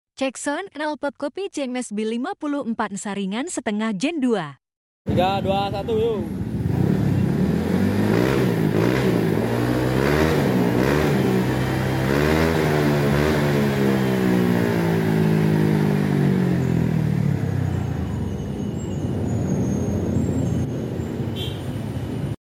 Cek Sound Bobokan Copy Cms Sound Effects Free Download